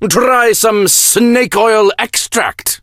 snakeoil_start_vo_01.ogg